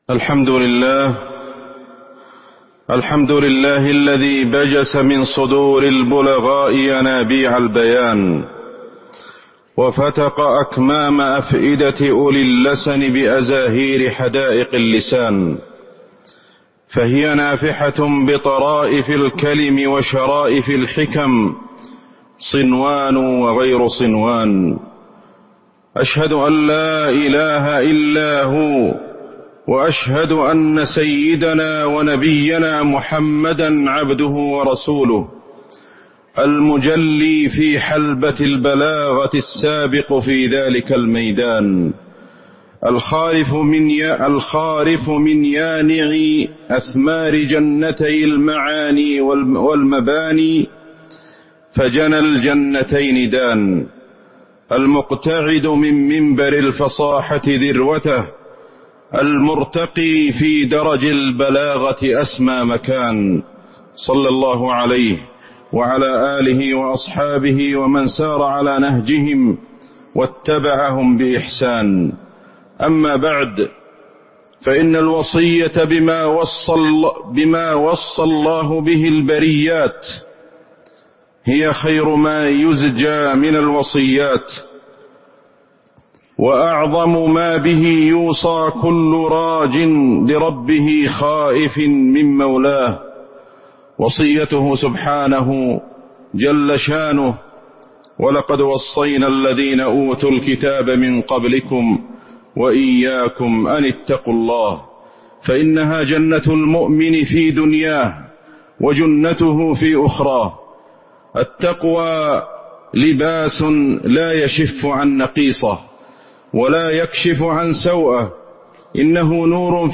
تاريخ النشر ٢٩ جمادى الأولى ١٤٤٤ هـ المكان: المسجد النبوي الشيخ: فضيلة الشيخ أحمد بن علي الحذيفي فضيلة الشيخ أحمد بن علي الحذيفي منطلق بيان رسالة الإسلام